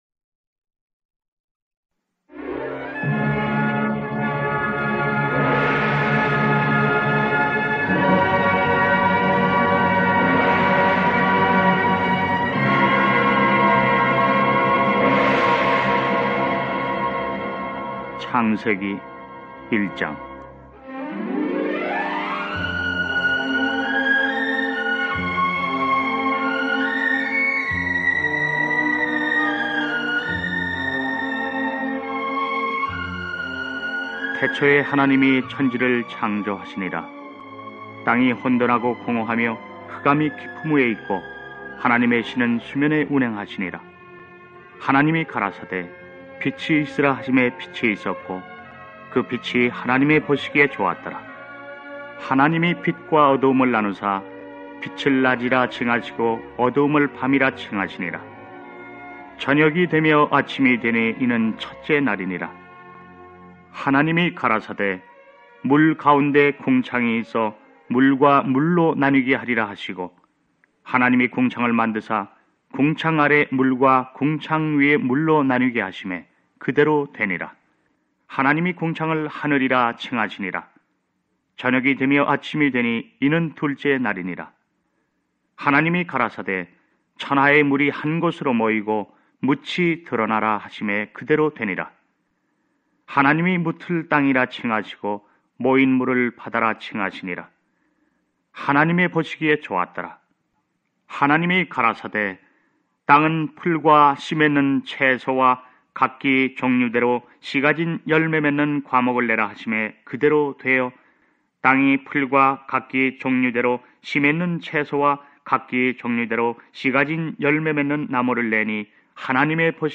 신.구약 DD성경은 성경 말씀 그대로 국내 정상급 믿음의 성우들이 출연하여 낭독한 최첨단 성경입니다.